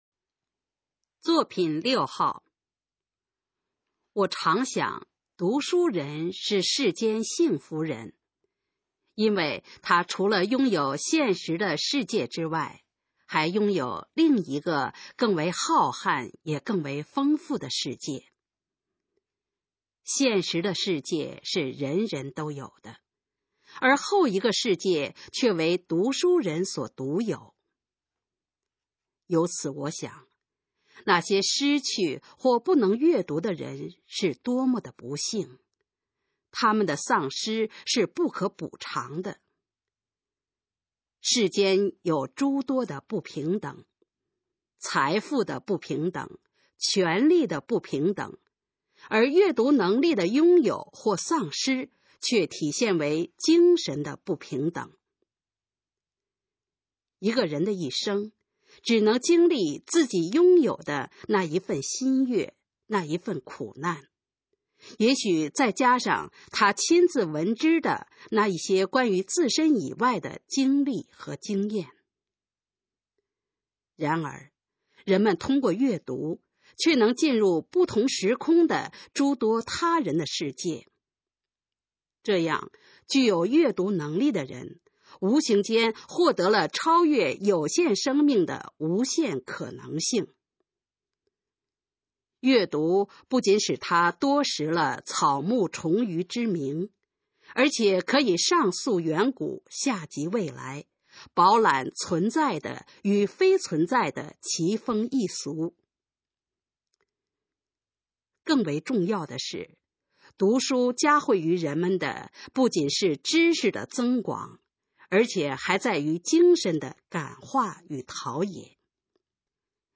《读书人是幸福》示范朗读_水平测试（等级考试）用60篇朗读作品范读